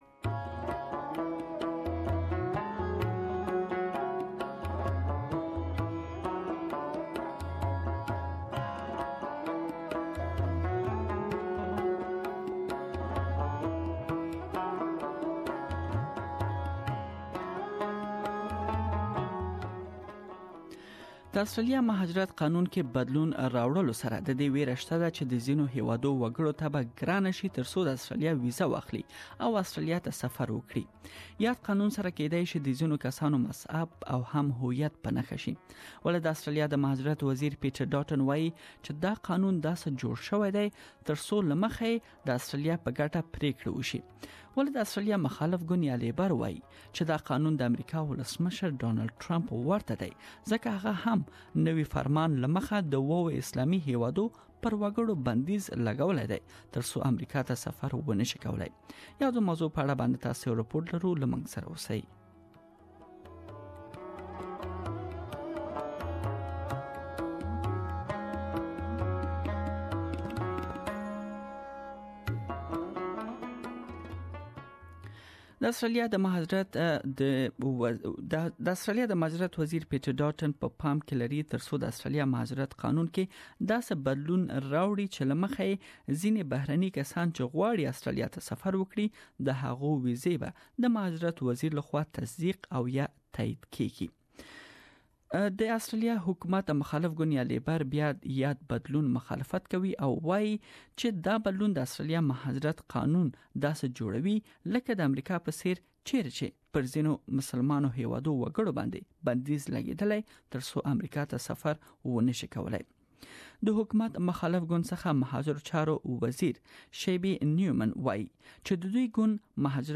There are fears proposed amendments to the Migration Act could exclude entire groups of people from living in or travelling to Australia based on their religion or nationality. Immigration Minister Peter Dutton says they're designed to protect Australia's national interest. But Labor has likened the proposed changes to U-S President Donald Trump's temporary immigration ban. Please listen to the full report here.